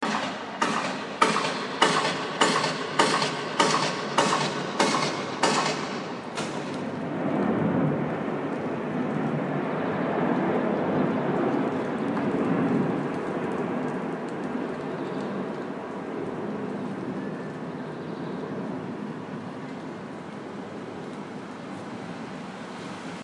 描述：街头工作：拆除人行道以建造新的人行道。
标签： 嘈杂的 工人 锤子 建设 工作 建设者 建设 拆除 建筑
声道立体声